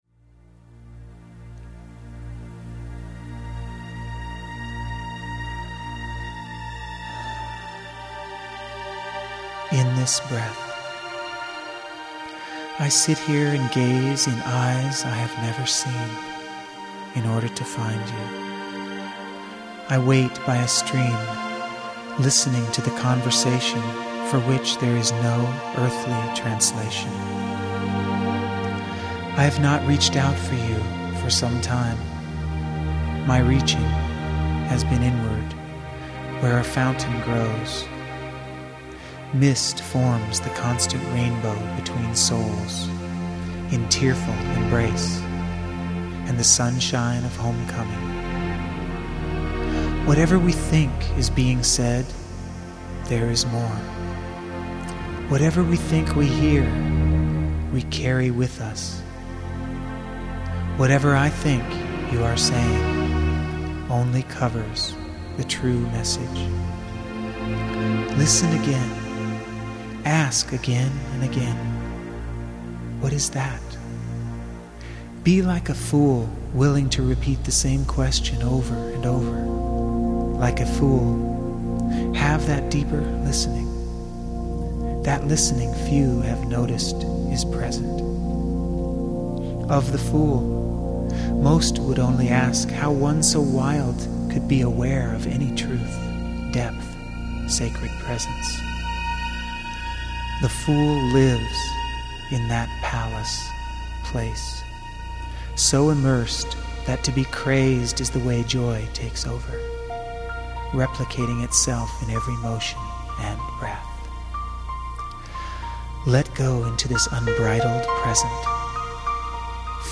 Ecstatic Poetry & Music for the Lover in All...